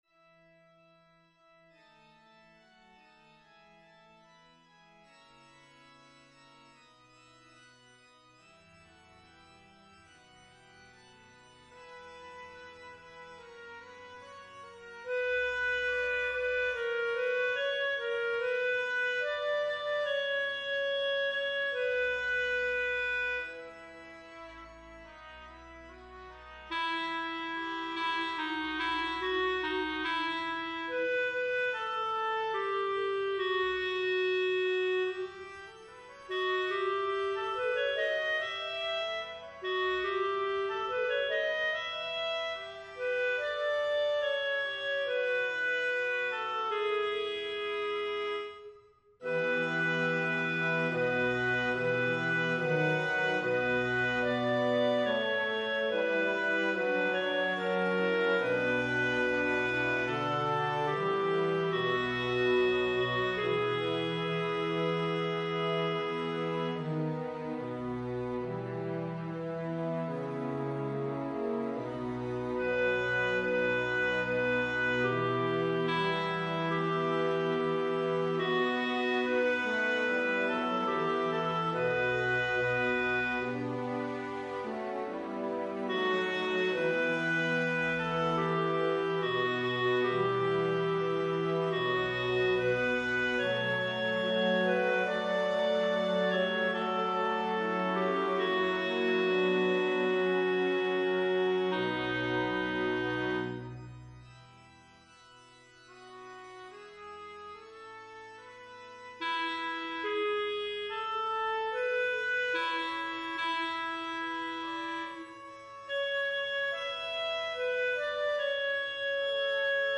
Soprano2
Evensong Setting